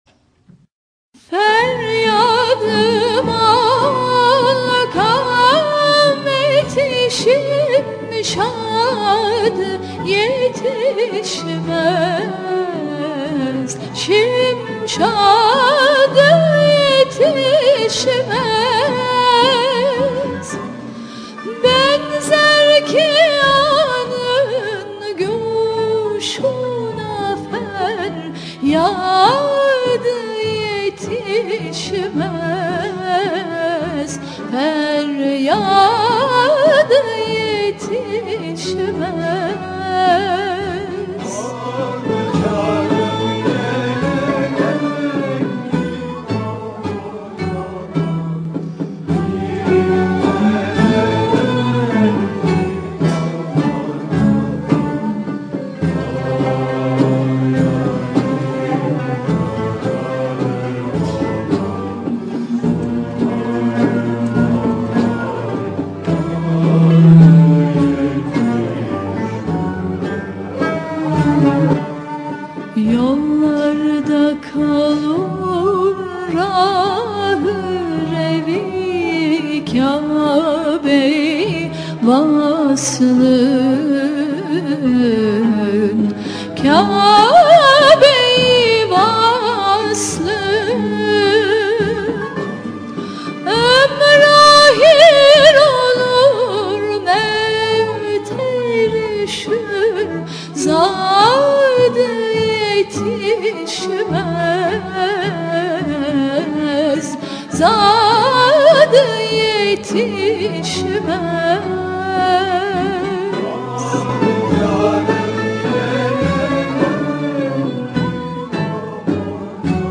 Usûl: Yürük Semai